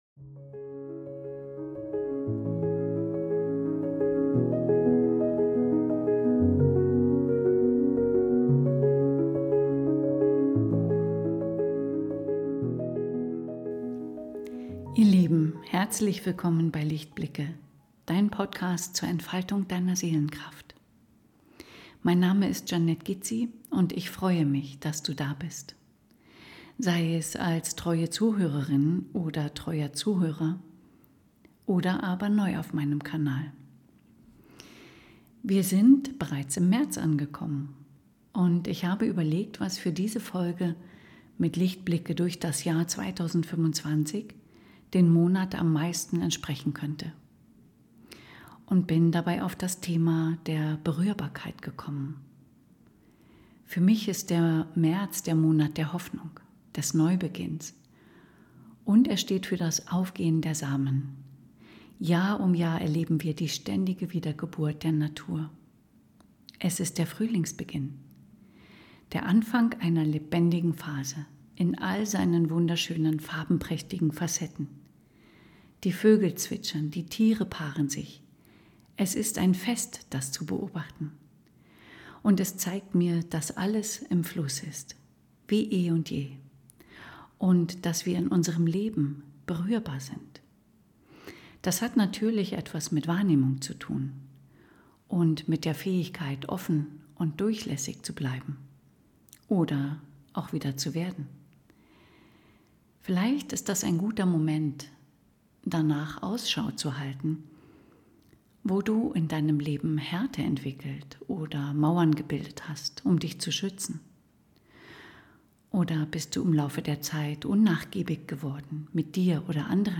Musik: Julius H / Pixabay
Beru--hrbarkeit_mit_Intro_-_Musik.mp3